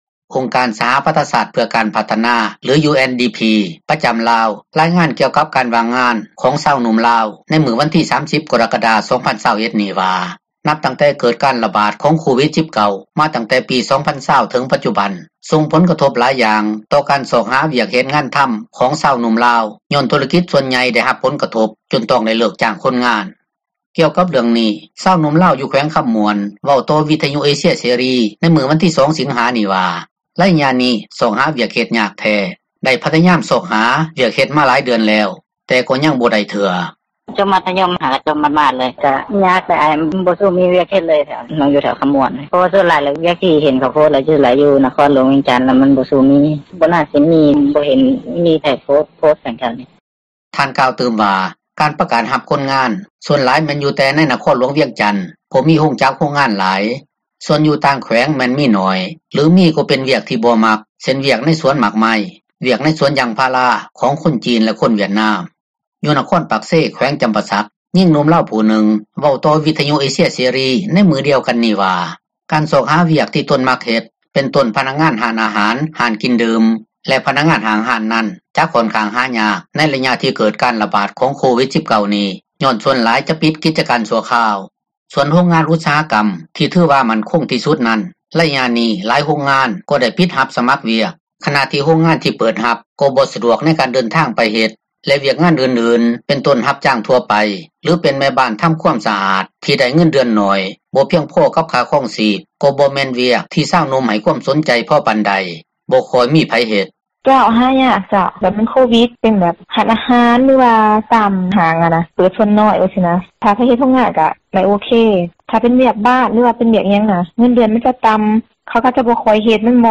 ກ່ຽວກັບເຣື່ອງນີ້ ຊາວໜຸ່ມລາວ ຢູ່ແຂວງຄໍາມ່ວນ ເວົ້າຕໍ່ວິທຍຸເອເຊັຍເສຣີ ໃນມື້ວັນທີ 2 ສິງຫາ ນີ້ວ່າໃນໄລຍະນີ້ ຊອກຫາວຽກເຮັດ ຍາກແທ້, ໄດ້ພຍາຍາມຊອກຫາວຽກເຮັດ ມາຫຼາຍເດືອນແລ້ວ ແຕ່ກໍຍັງບໍ່ໄດ້ເທື່ອ:
ຢູ່ນະຄອນປາກເຊ ແຂວງຈໍາປາສັກ ຍິງໜຸ່ມລາວຜູ້ນຶ່ງເວົ້າຕໍ່ ວິທຍຸເອເຊັຍເສຣີ ໃນມື້ດຽວກັນນີ້ວ່າ ການຊອກຫາວຽກທີ່ຕົນມັກເຮັດ ເປັນຕົ້ນ ພະນັກງານຮ້ານອາຫານ ຮ້ານກິນດື່ມ ແລະ ພະນັກງານຫ້າງຮ້ານນັ້ນ ຈະຂ້ອນຂ້າງຫາຍາກ ໃນໄລຍະທີ່ເກີດການຣະບາດ ຂອງໂຄວິດ-19 ນີ້ ຍ້ອນສ່ວນຫລາຍ ຈະປິດກິຈການຊົ່ວຄາວ.